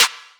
DDW Snare 2.wav